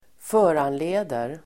Uttal: [f'ö:ranle:der]